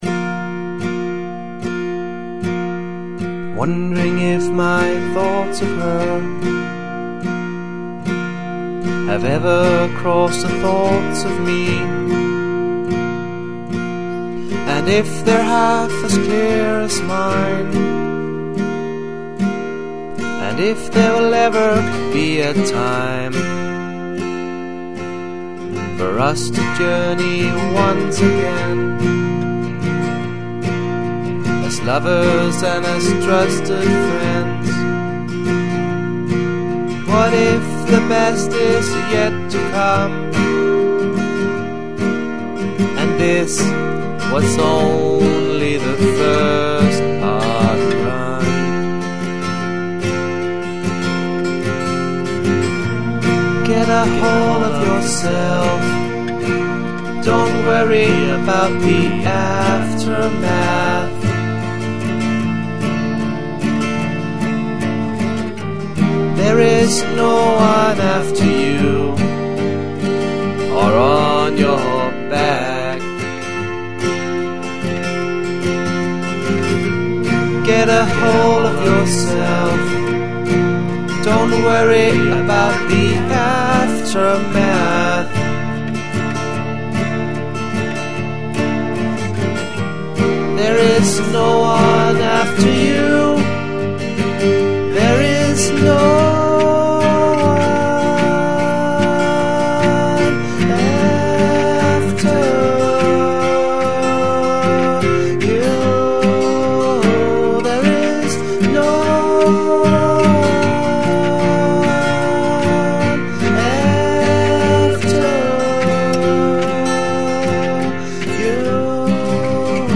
Sangene er alle gemt i internet-lydkvalitet